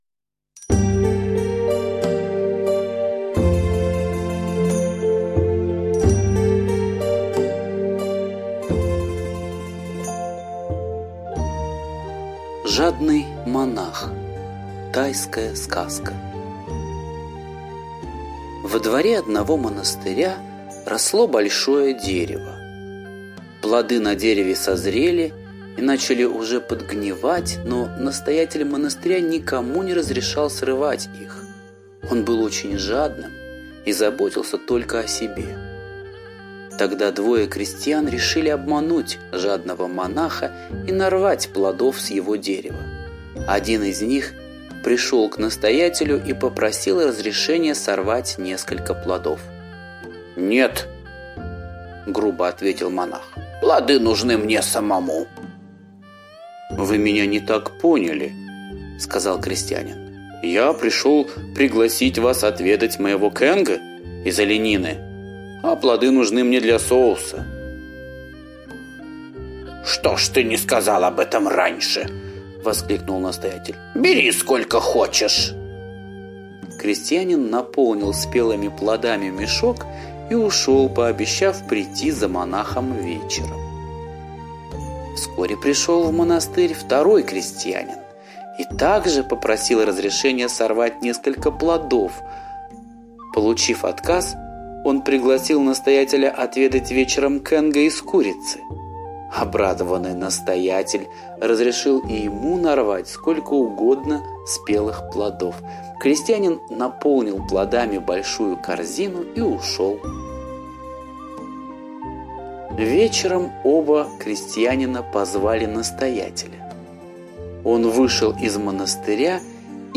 Жадный монах - восточная аудиосказка - слушать онлайн